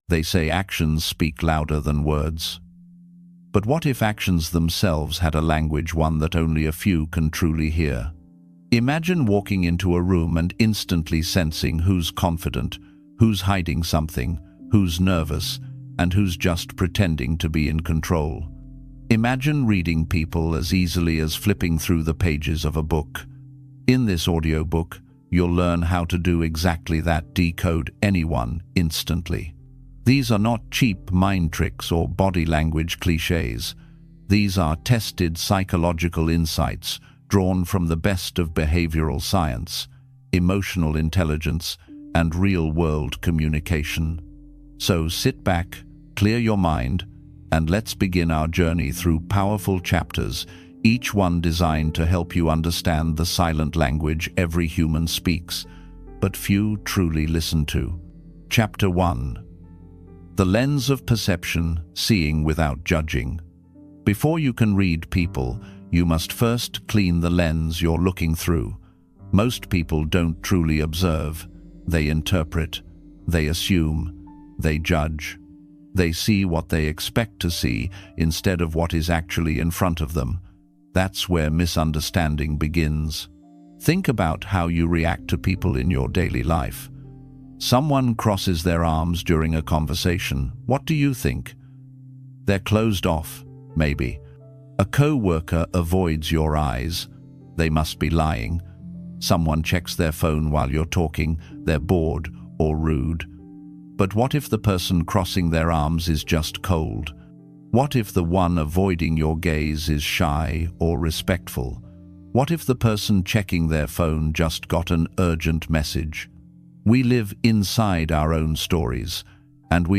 13 Simple Habits to Unlock Your Best Self | Personal Growth Audiobook